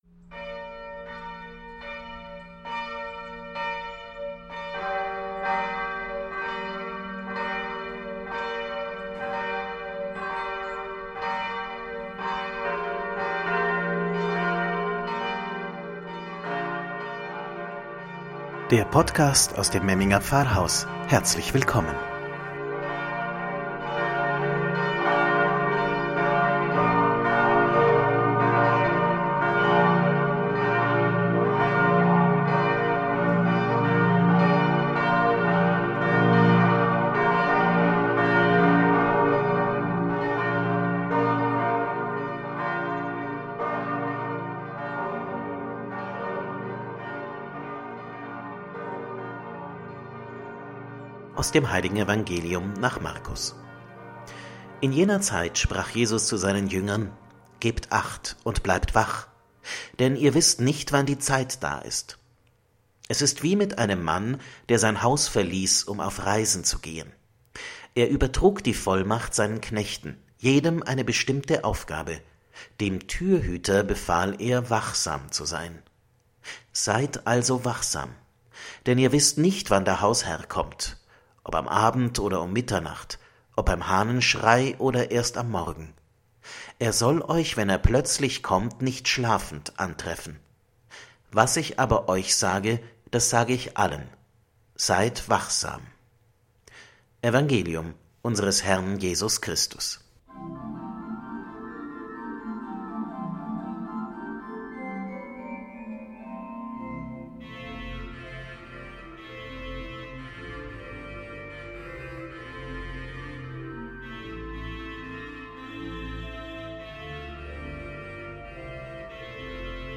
„Wort zum Sonntag“ aus dem Memminger Pfarrhaus – Erster Adventsonntag 2020